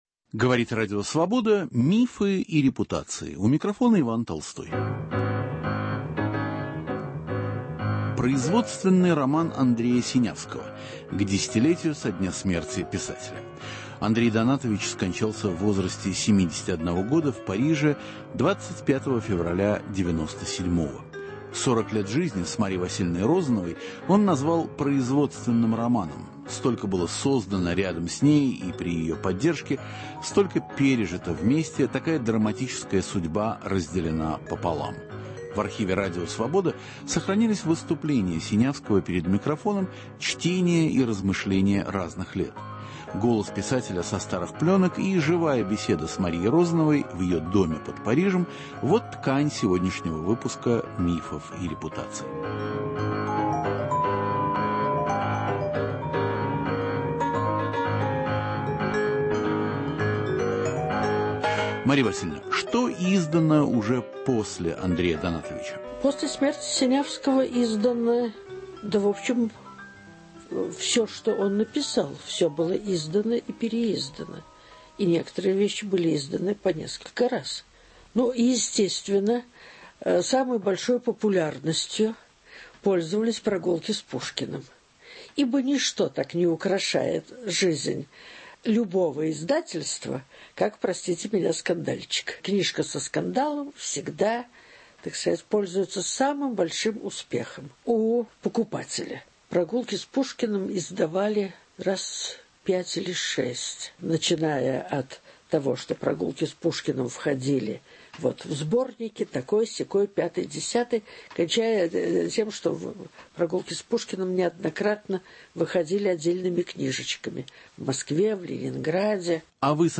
В программе прозвучат архивные записи, выступления Синявского и живая беседа с Марией Розановой, записанная в ее доме под Парижем.